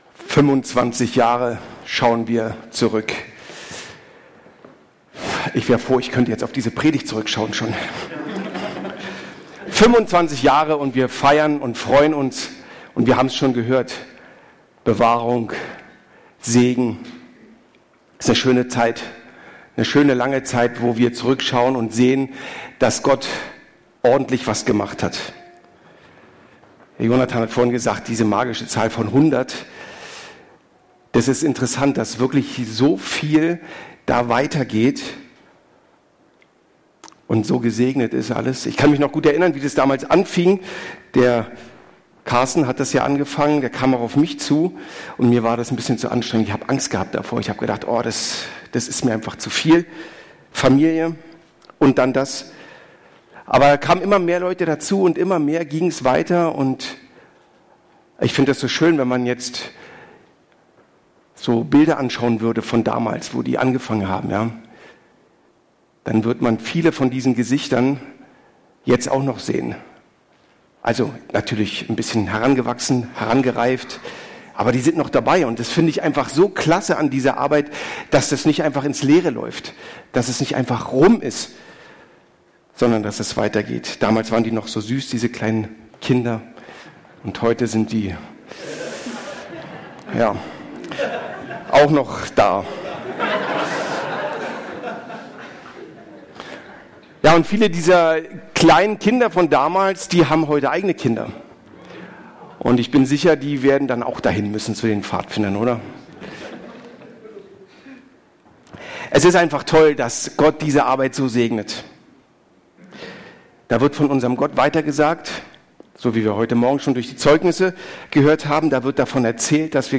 Predigt vom 10.09.2023